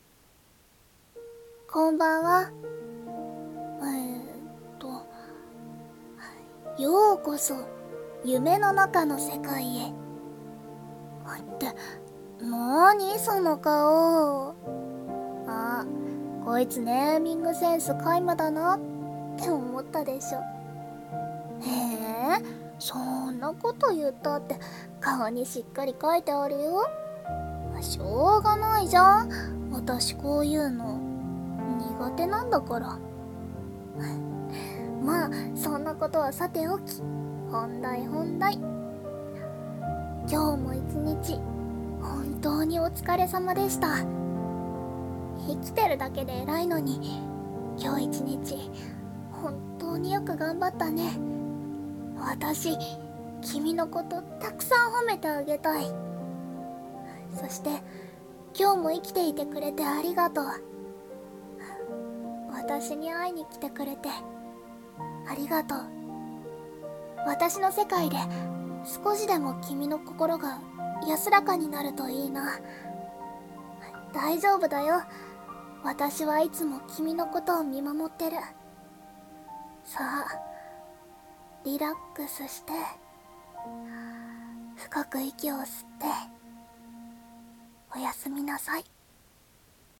【声劇】夢の中からおやすみ【１人声劇】